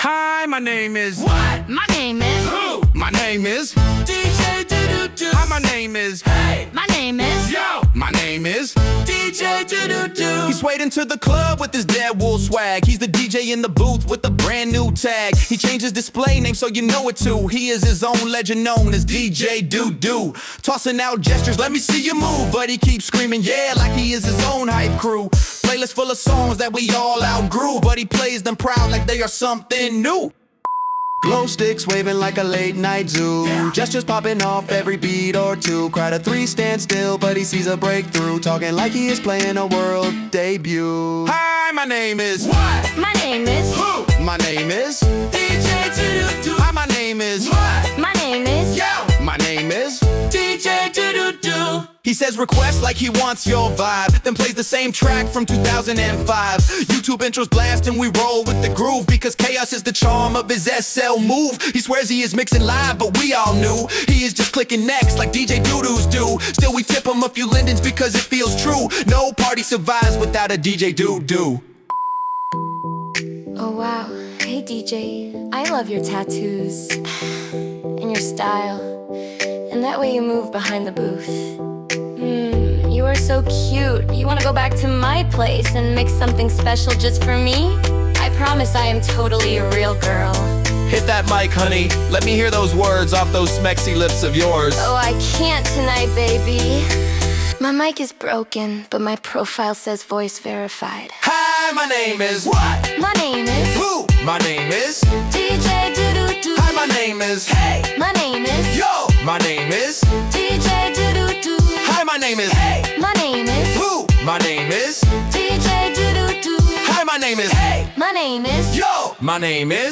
A Second Life parody song celebrating those unintentionally entertaining SL Dj’s
So I wrote a parody that celebrates that whole vibe.
“DJ Doo Doo” is silly, affectionate, and very real.